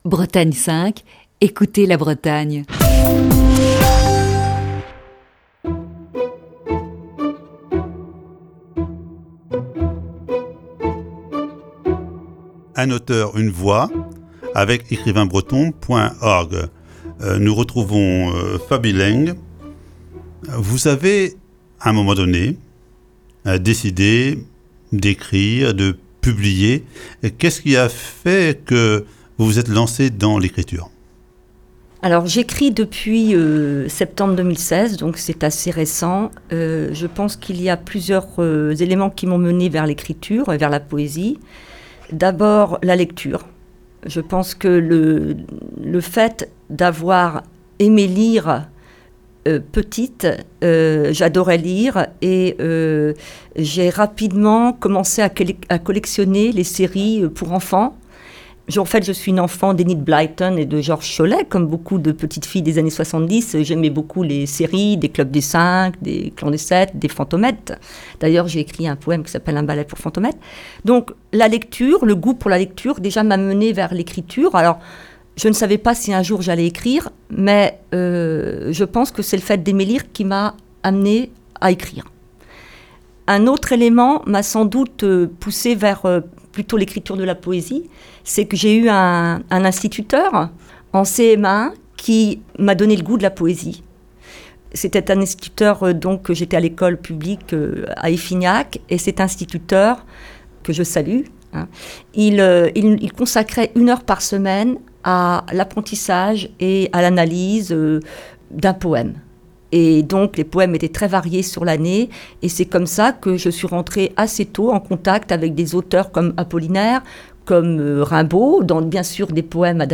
(Émission diffusée le 9 octobre 2019).